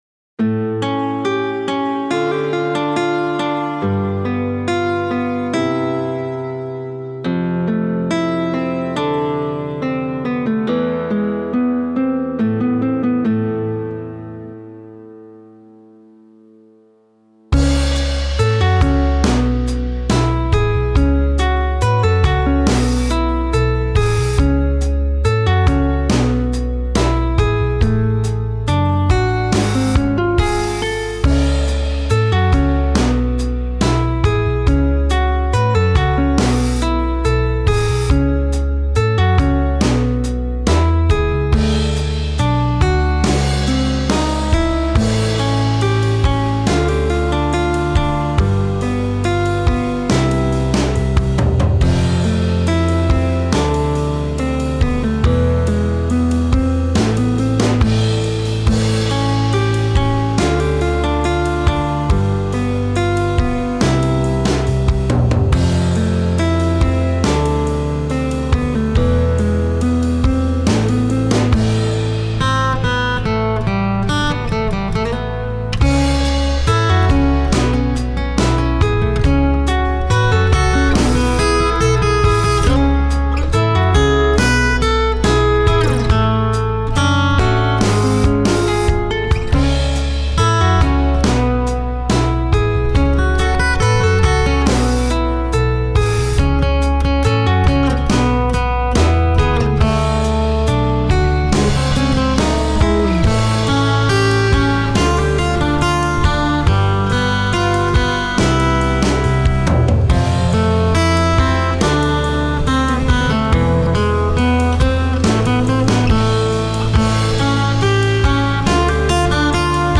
Красивая мелодия студенческих лет.